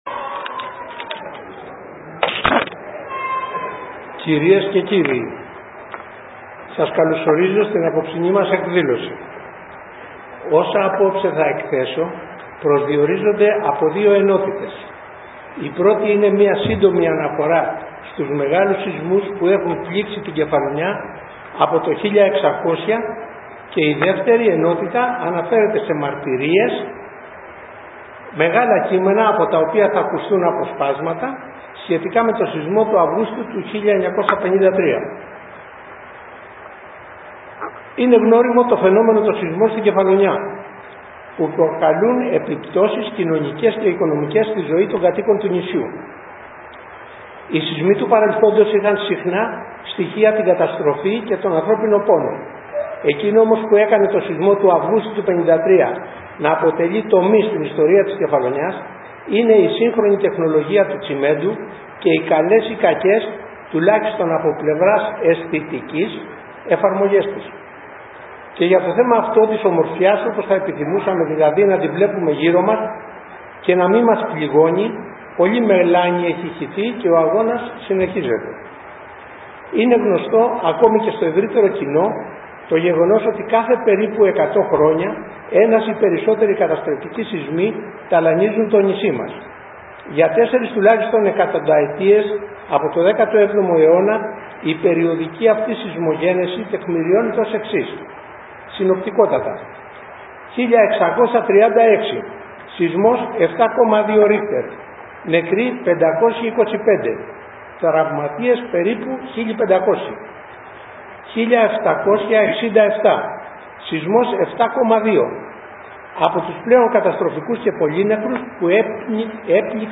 SEANEMA: Ημερίδα για τους σεισμούς